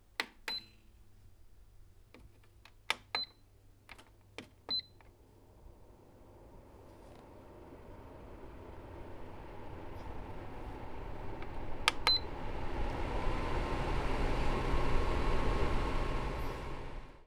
Original creative-commons licensed sounds for DJ's and music producers, recorded with high quality studio microphones.
air purifier with beeps.wav
A Camry Air Purifier turned on on all three intensity steps, in a living room, recorded with a TASCAM DR 40.
air_purifier_with_beeps_Rkm.wav